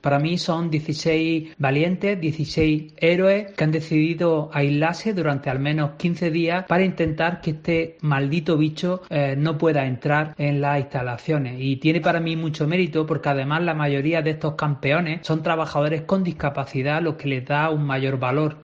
en declaraciones a COPE Almería sobre la decisión de los trabajadores